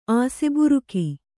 ♪ āseburuki